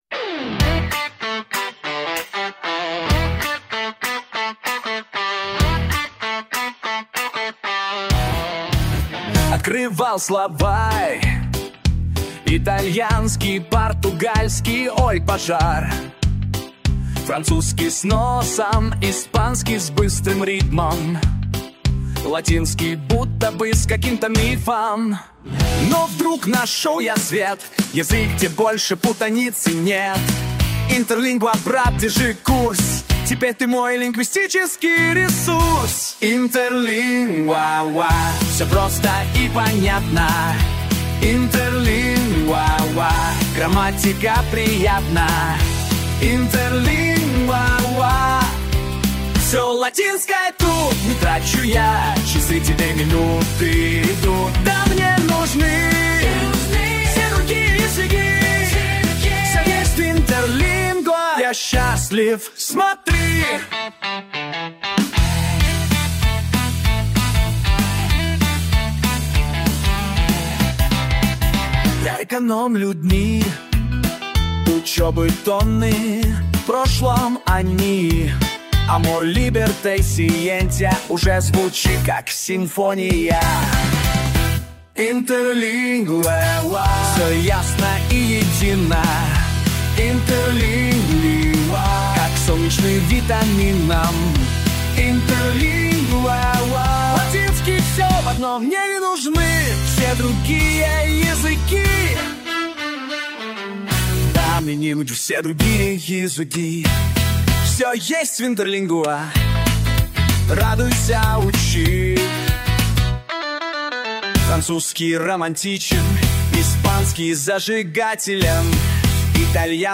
• Песня про Интерлингву на русском